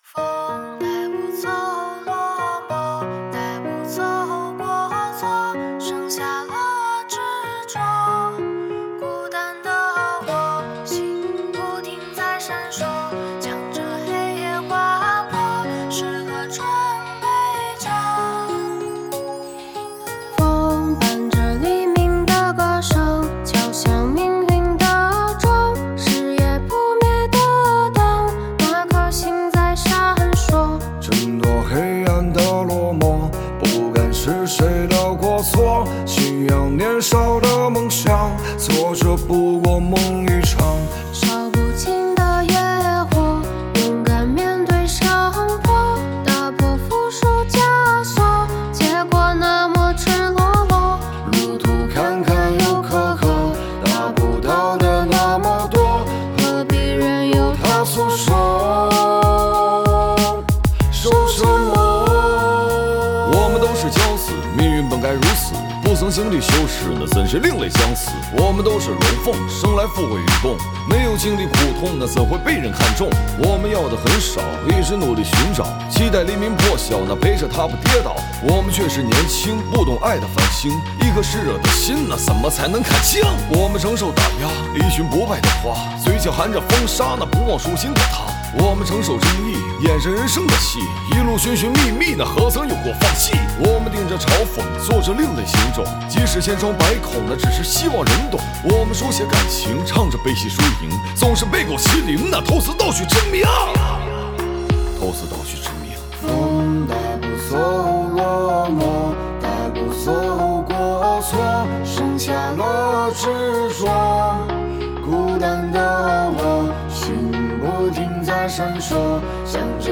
Ps：在线试听为压缩音质节选，体验无损音质请下载完整版
男女合唱版